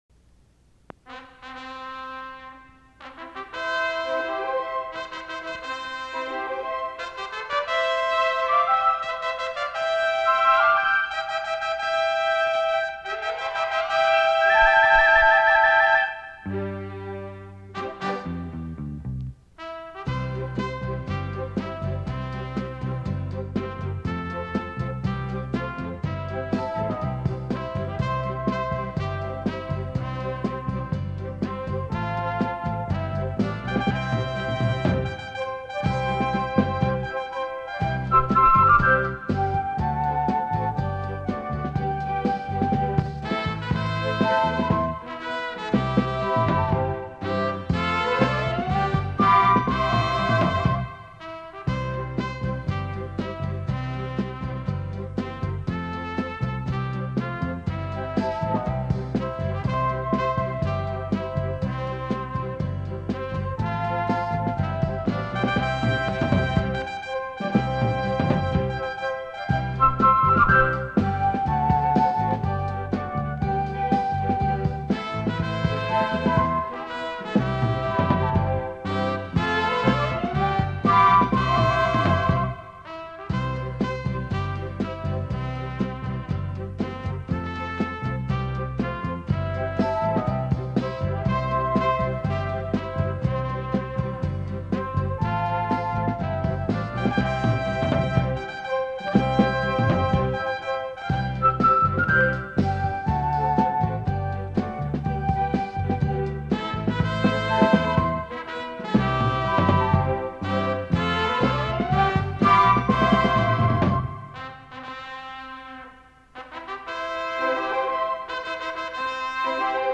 太子町歌（伴奏のみ）（MP3：1.7MB） (音声ファイル: 1.8MB)